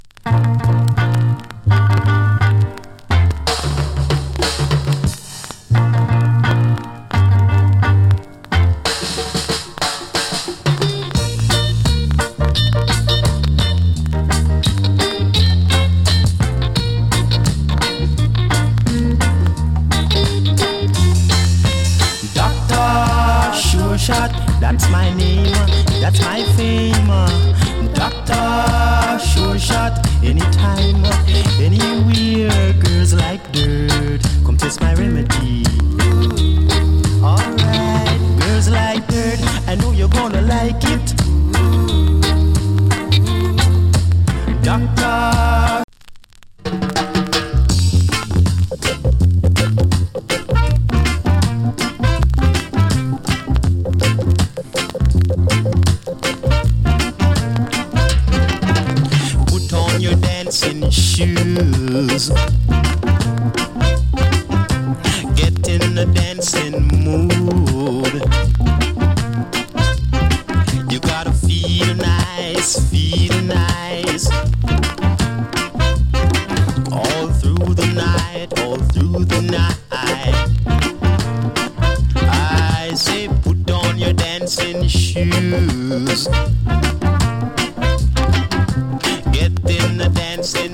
68年 UK RECORDING. NICE ROCK STEADY !!